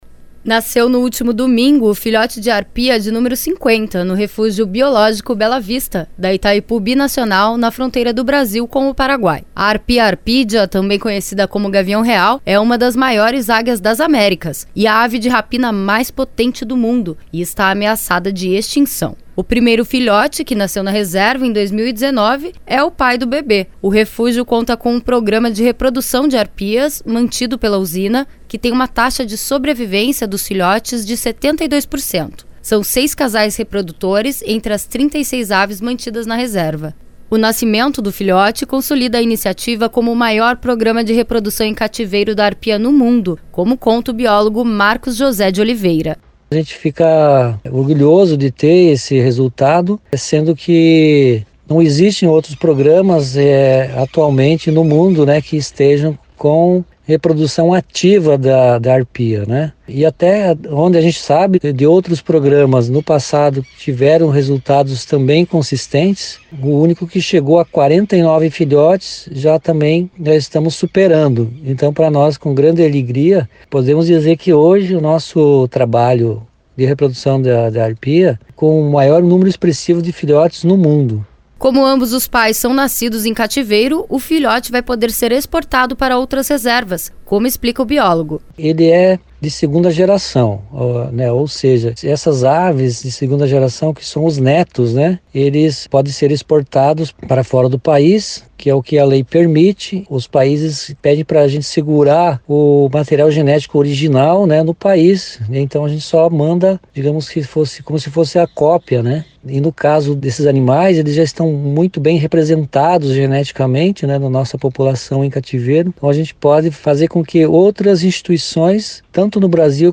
Como ambos os pais são nascidos em cativeiro, o filhote vai poder ser exportado para outras reservas, como explica o biólogo.